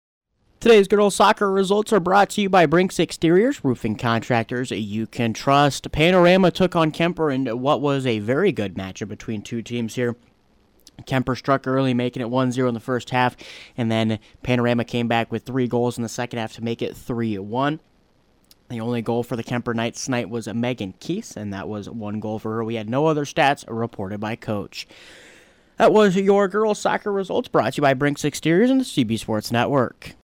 Below is an Audio Recap of girls’ soccer results from Friday, April 17th